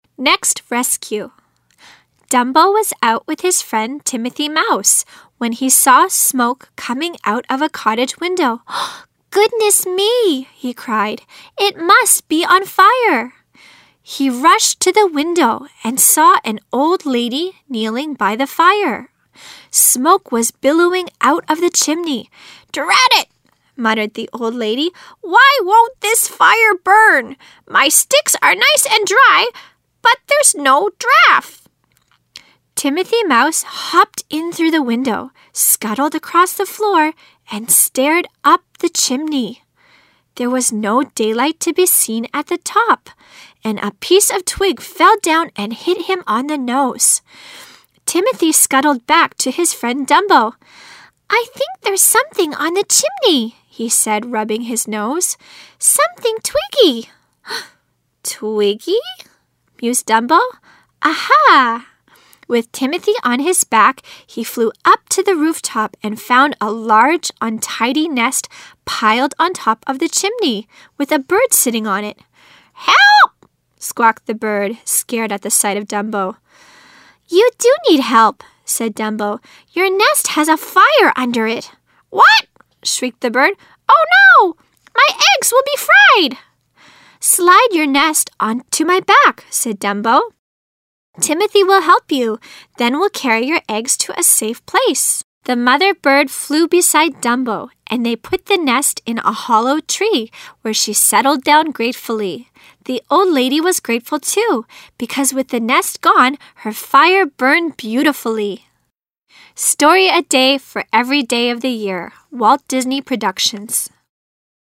Nest Rescue 女聲版 （最近一週新上傳檔案）
第一篇-Nest Rescue 女聲版.mp3